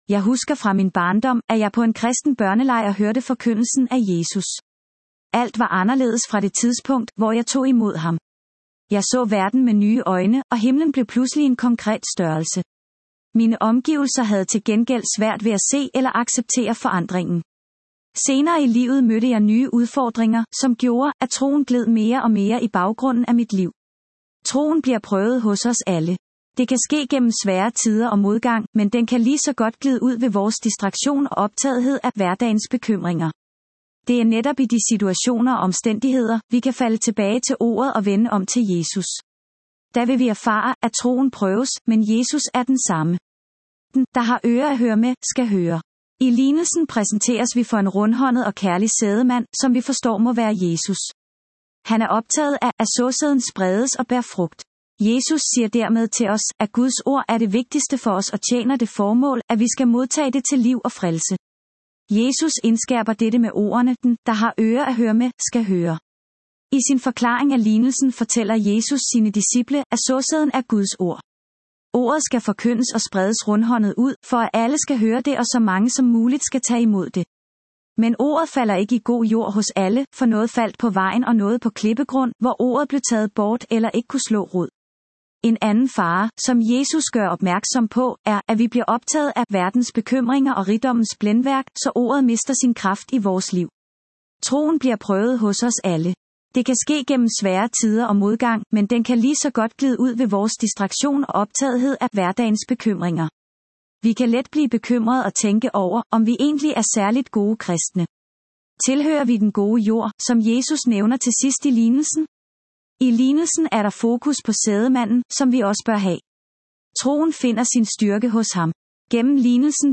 Ugens Prædiken